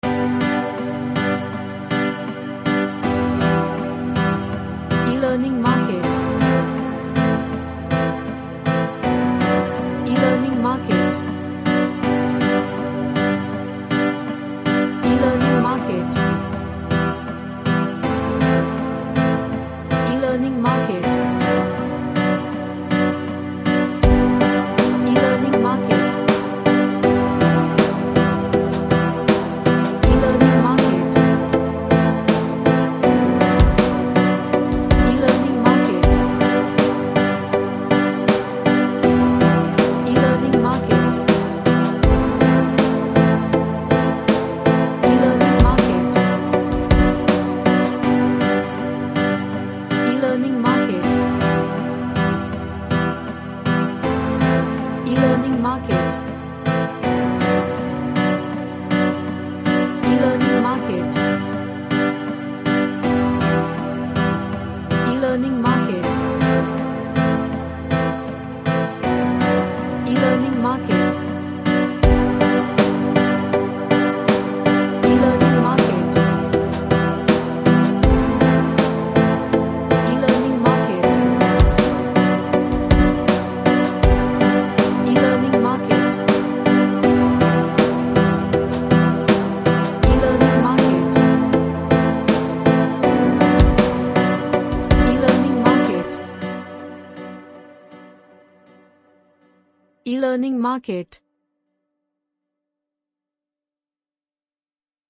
A reggae track with ambient vibe.
Relaxation / Meditation